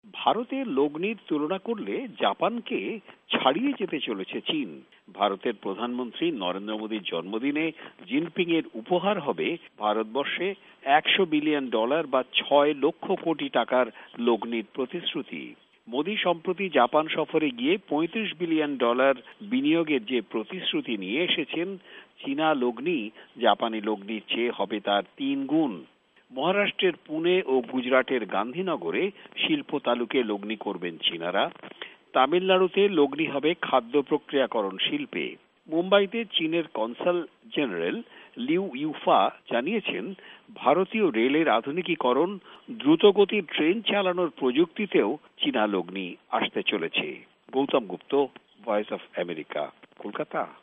ভয়েস অফ এ্যামেরিকার কলাকাতা সংবাদদাতাদের রিপোর্ট